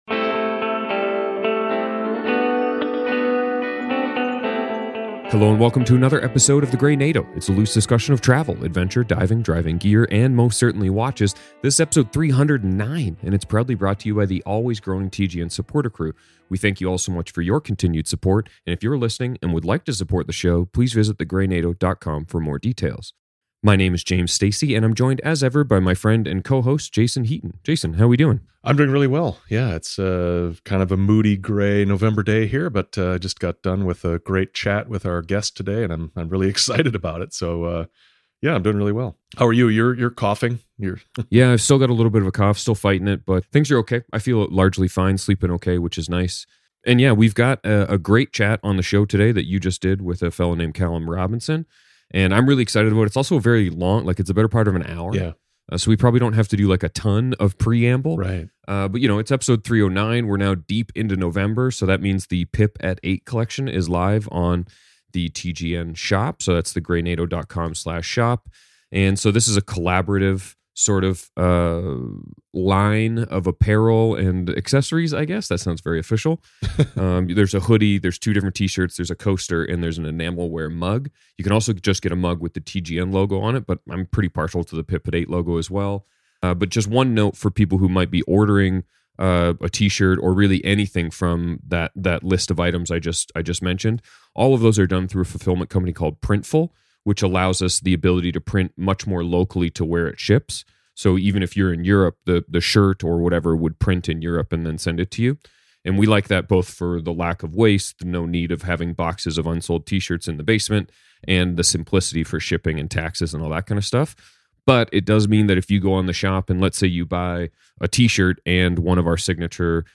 The hosts bookend the interview with announcements about their TGN merchandise collection and upcoming schedule changes, followed by their regular "risk check" segment discussing their current watch choices. They close with recommendations for entertainment and lifestyle products, including a new spy thriller series and the nostalgic appeal of lava lamps.